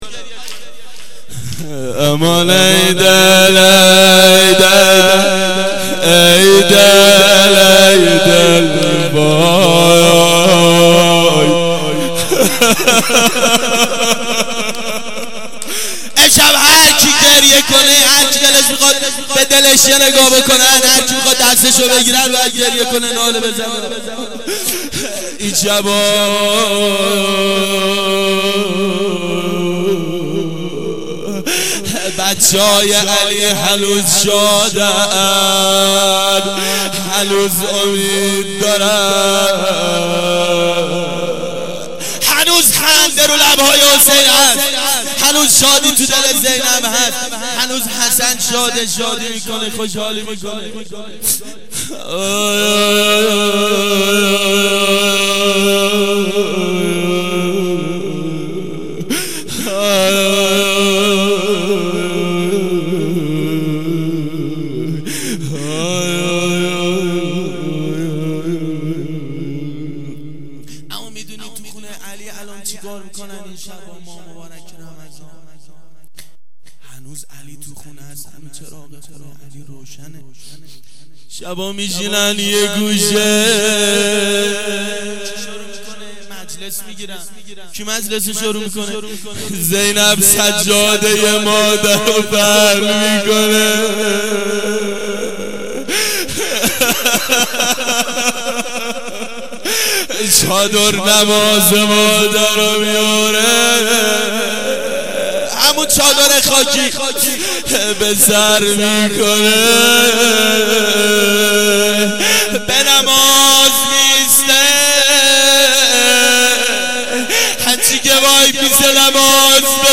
روضه امیرالمؤمنین علیه السلام
گلچین جلسات هفتگی سال 1387
روضه-امیرالمؤمنین.mp3